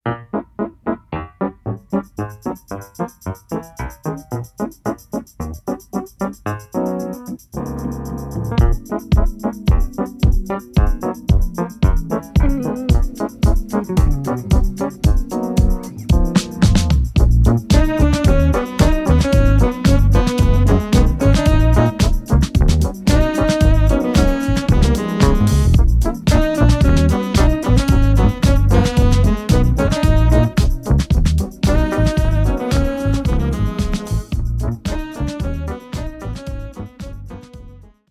trumpet & saxophone